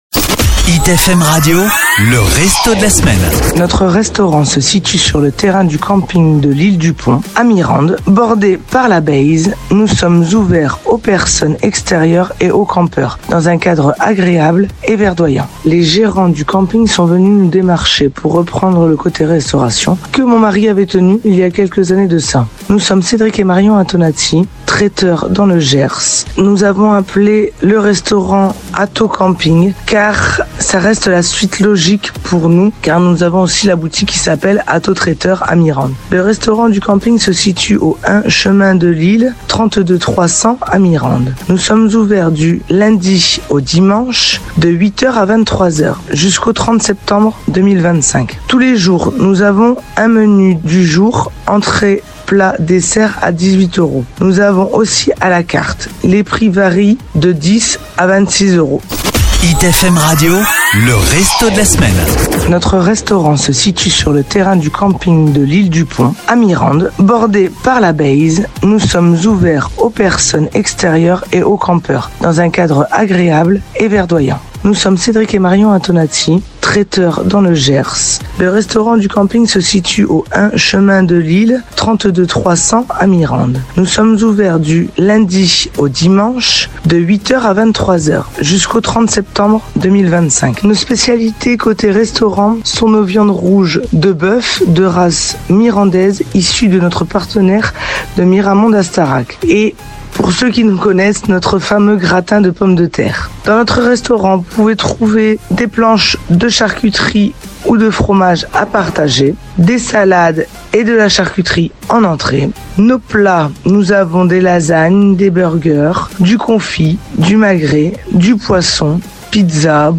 Cette semaine, Hit FM pose ses micros à Mirande, sur le charmant terrain du camping de L’Isle du Pont, pour découvrir le restaurant Atto Camping.